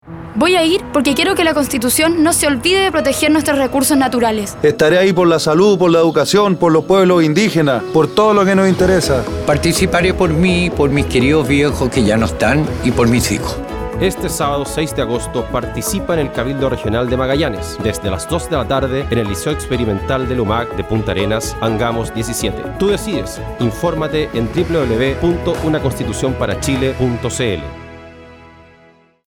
Audio promoción testimonial cabildos regionales, Región de Magallanes y de la Antártica Chilena 2